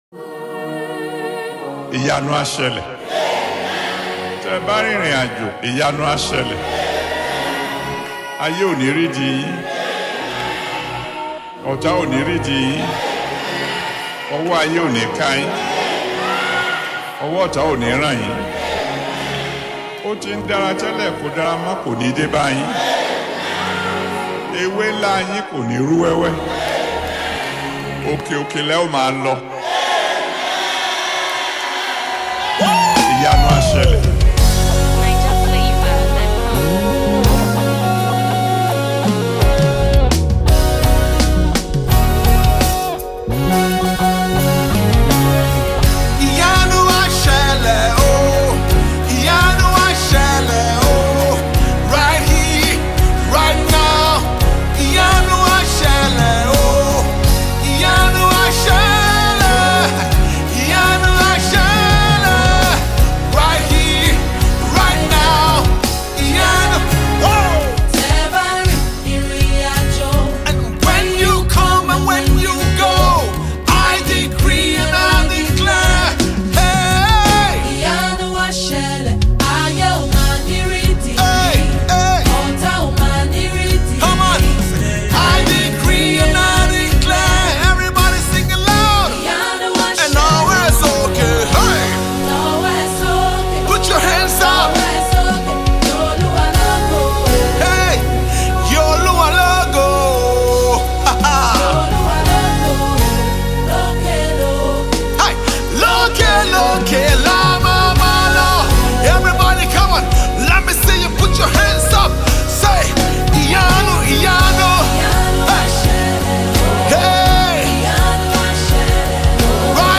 heart melting prophetic song